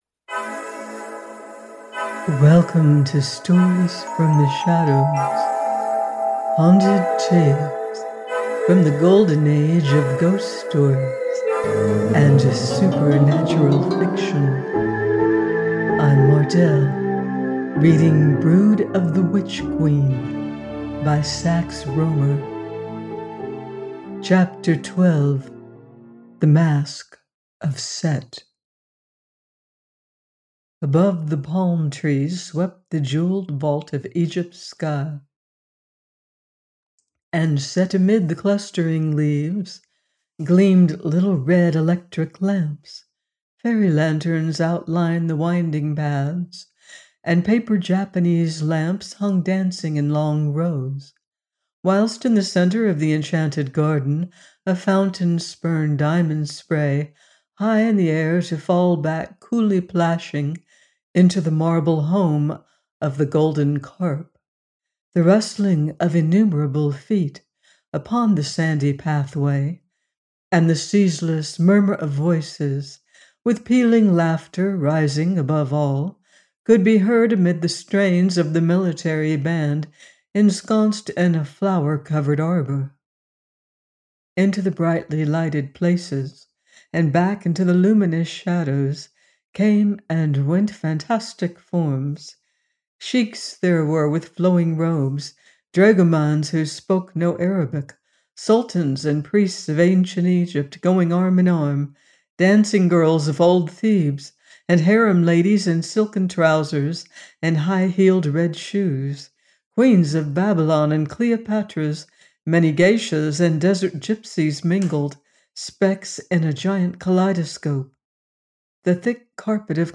Brood of the Witch Queen – 12 : by Sax Rohmer - AUDIOBOOK